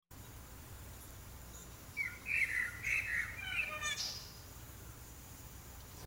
Birds Chirping #3 | TLIU Studios
Category: Animal Mood: Calming Editor's Choice